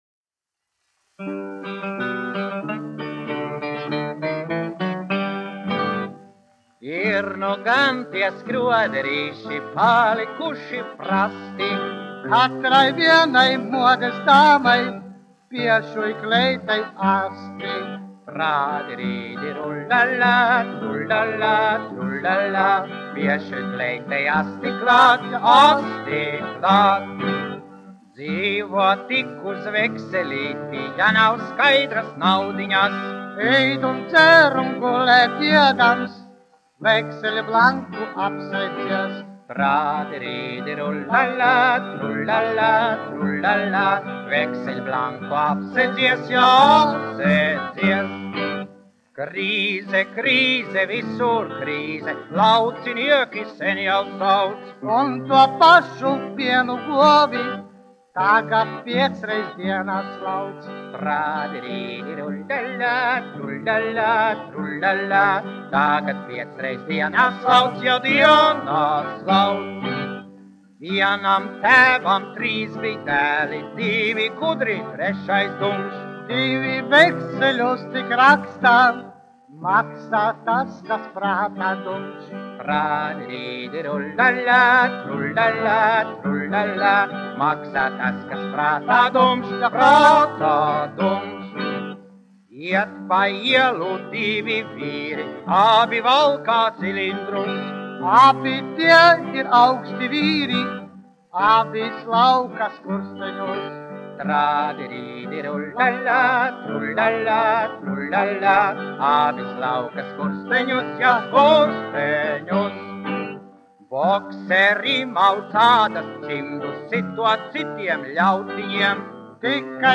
1 skpl. : analogs, 78 apgr/min, mono ; 25 cm
Humoristiskās dziesmas